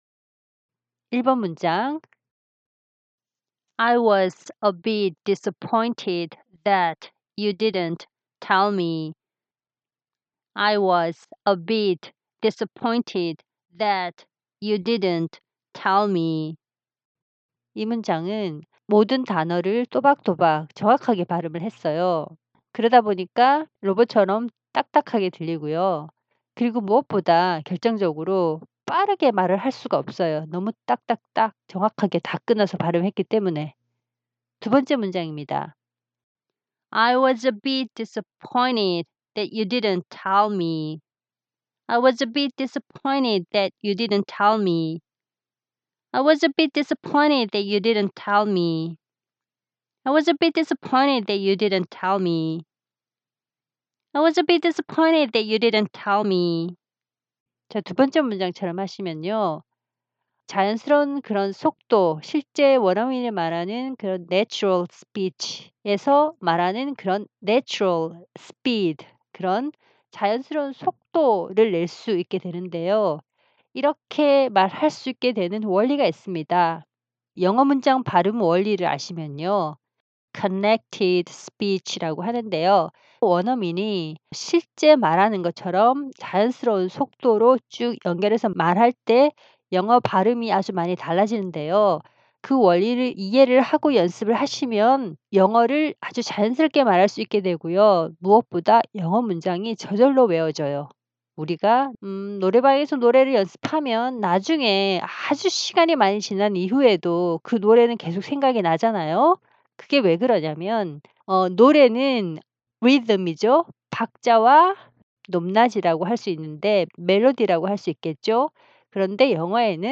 저의 목소리로 이런 원리 설명도 들으면서 여러분도 한 번 같이 연습 해봐요.